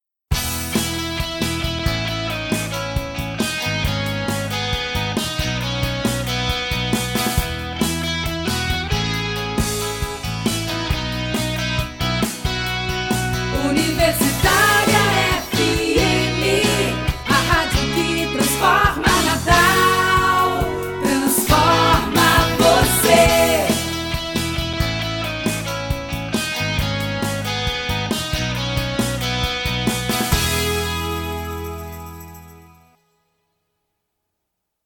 Spots e vinhetas
Seja com locução feminina, masculina, interpretativa ou caricata, o spot vai desde o rádio ao podcast levando, de forma clara e objetiva, sua mensagem para o seu público-alvo.